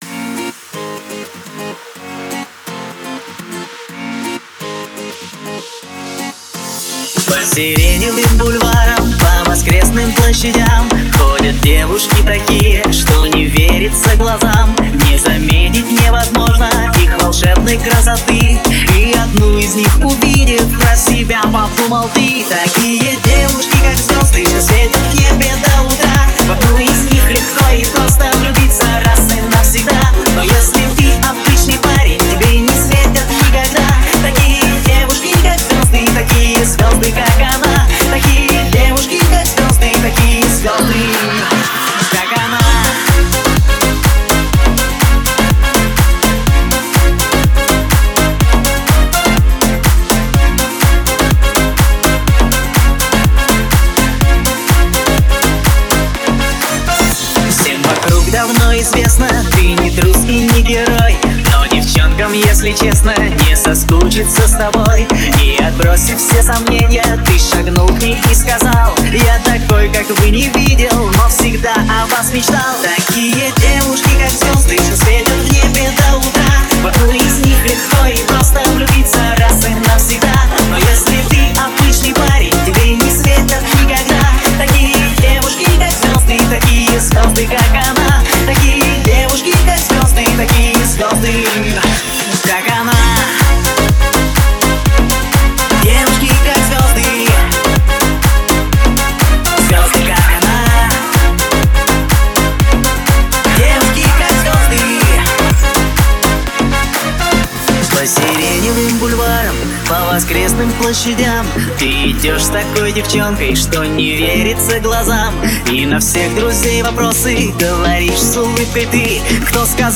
Жанр: Pop, Dance, Other